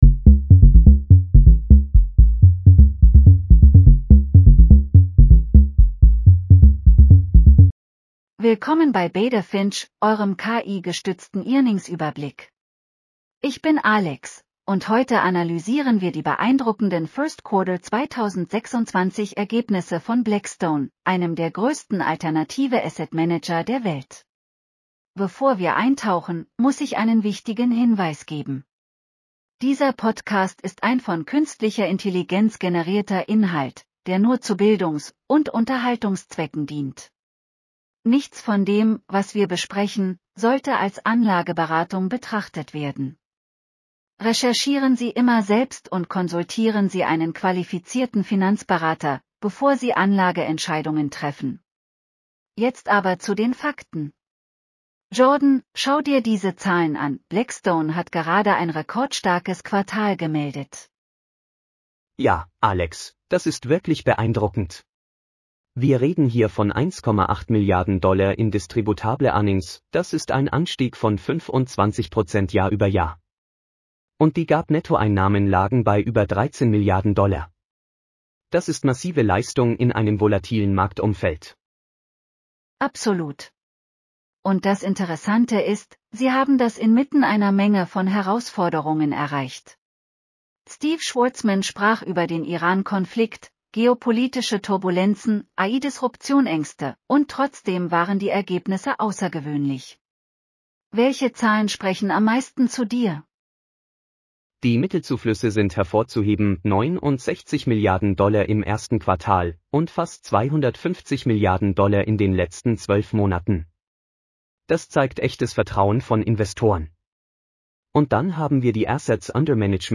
Willkommen bei Beta Finch, eurem KI-gestützten Earnings-Überblick!